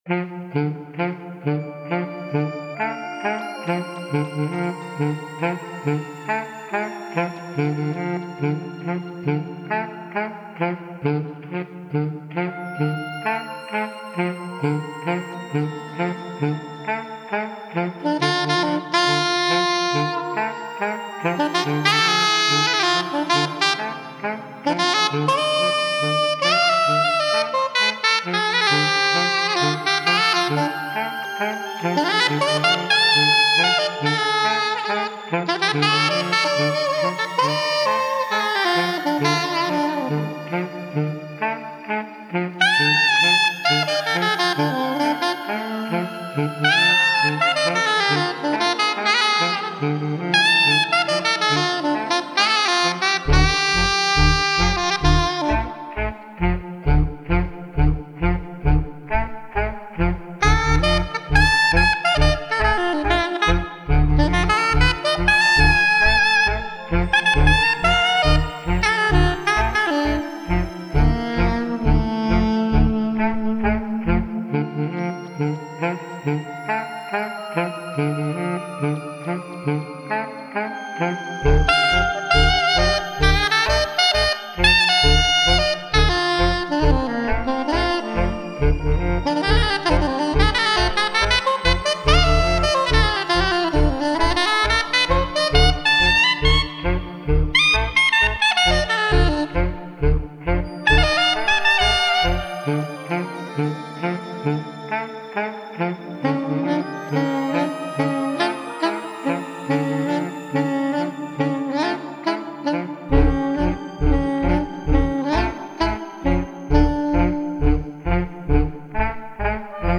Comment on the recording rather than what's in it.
Recorded at home in Manhattan May 30, 2015 Stereo (Pro Tools)